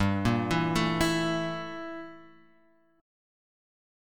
Gm6add9 chord {3 1 2 2 x 0} chord